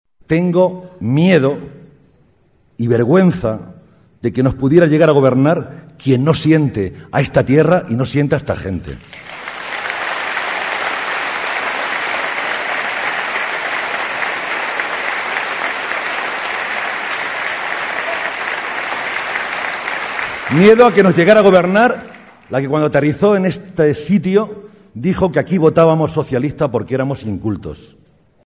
El presidente de Castilla-La Mancha y candidato a la reelección, José María Barreda, fue recibido por unas 1.100 personas en el Teatro Quijano de Ciudad Real a gritos de «presidente, presidente», donde advirtió de la importancia de las próximas elecciones, ya que, dijo, «nos jugamos el futuro de nuestro sistema sanitario, la educación de nuestros hijos y el bienestar de nuestros mayores».